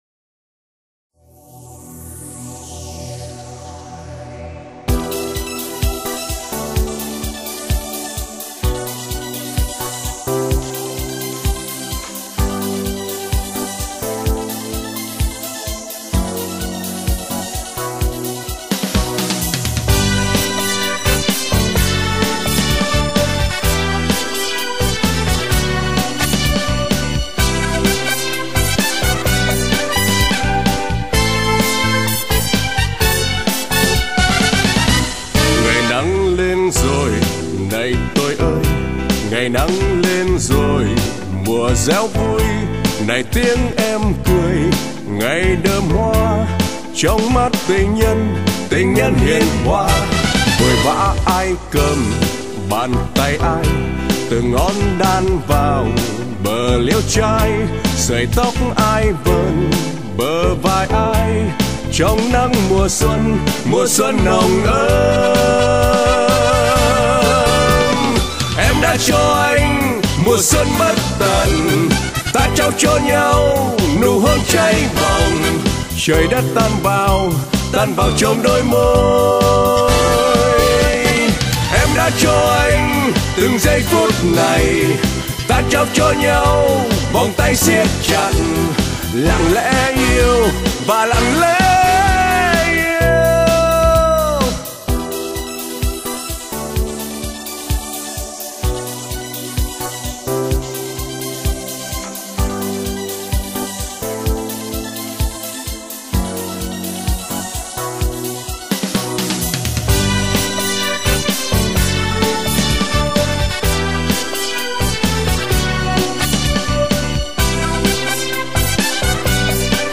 13/04/2012 in Âm Nhạc